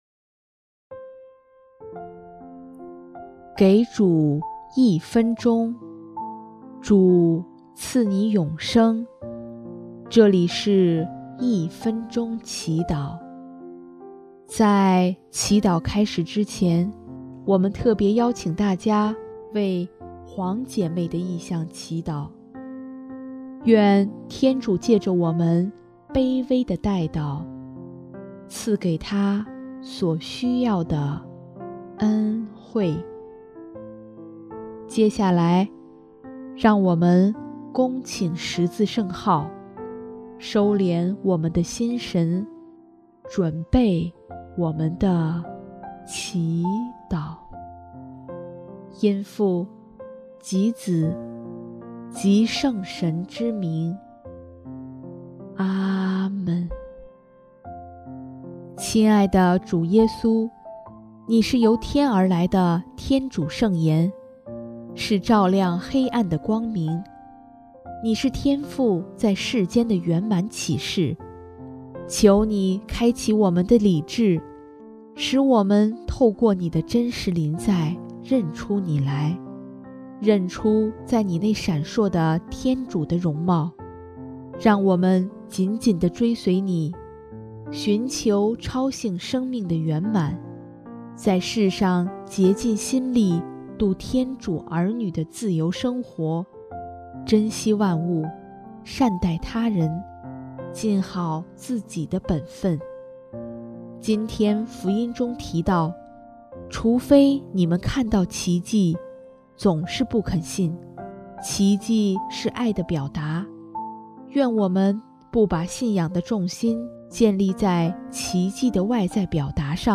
【一分钟祈祷】|3月11日 那些没有看见而信的人更为有福